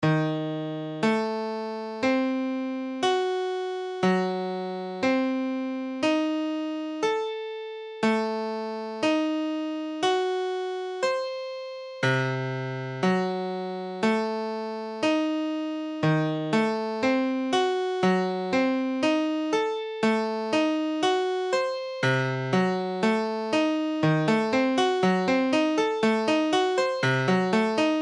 Tablature Ebdim7.abcEbdim7 : accord de Mi bémol septième diminuée
Mesure : 4/4
Tempo : 1/4=60
A la guitare, on réalise souvent les accords de quatre notes en plaçant la tierce à l'octave.
Forme fondamentale : tonique quinte diminuée sixte tierce mineure
Ebdim7.mp3